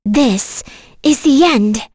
Kirze-Vox_Skill7.wav